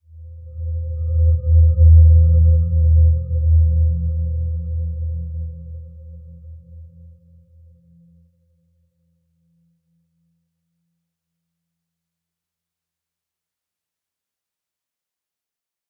Dreamy-Fifths-E2-mf.wav